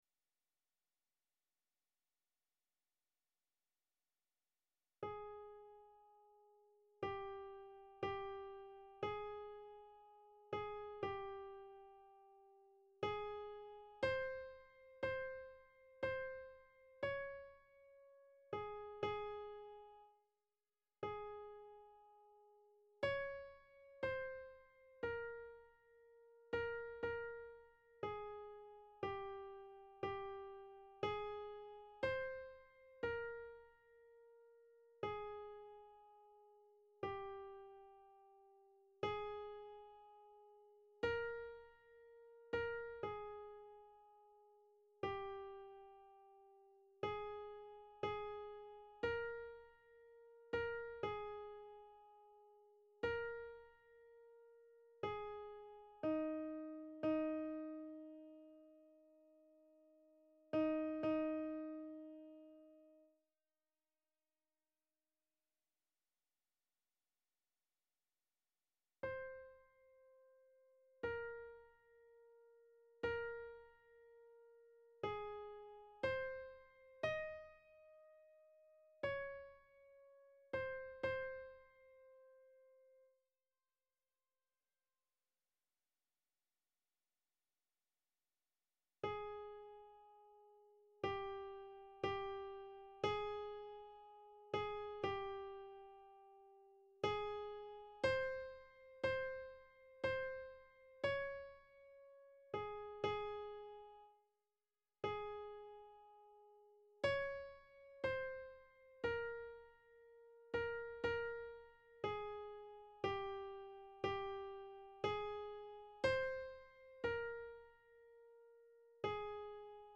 Répétition SATB par voix
Panis angelicus_tenor,.mp3